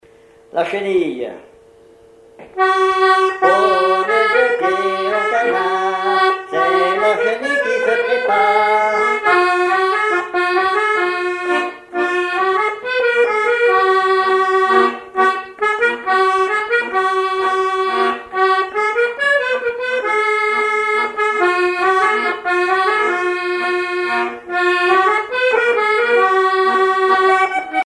accordéon(s), accordéoniste
danse : marche
Répertoire à l'accordéon chromatique
Pièce musicale inédite